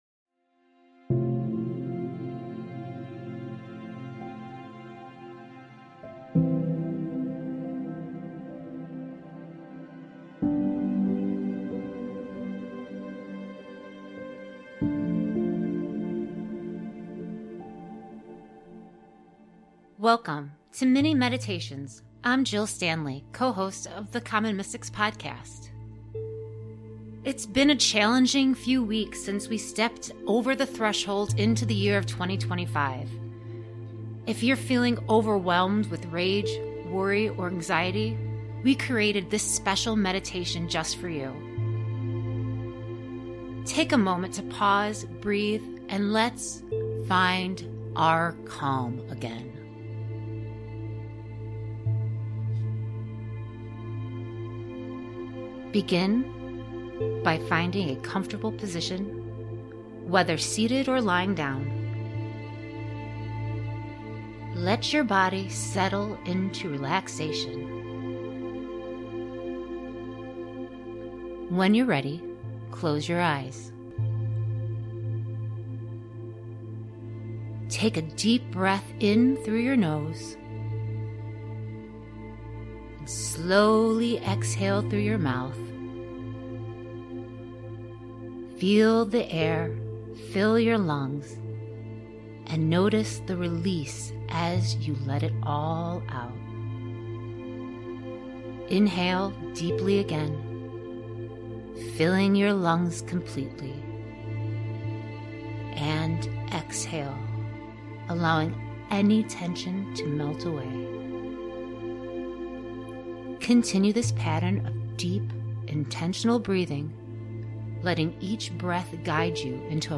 Common Mystics' Mini Meditation for Releasing Rage